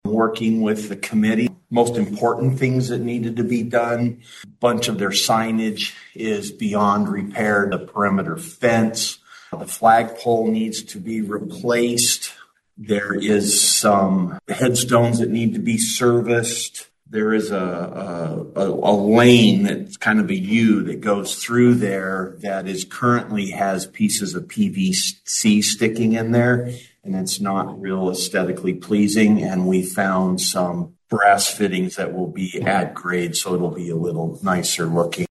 County Parks, Fairs and Facilities Director Don Crawford says they’re working with the group that oversees the cemetery.